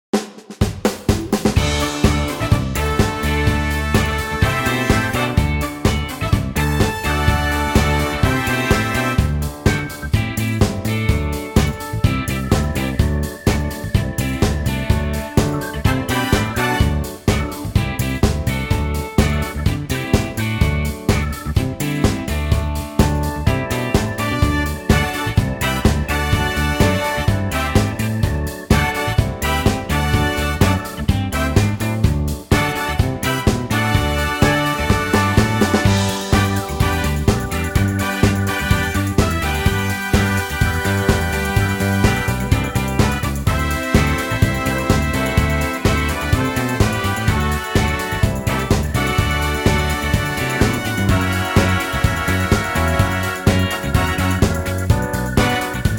key - D - vocal range - C to A (optional B)
Superb new punchy arrangement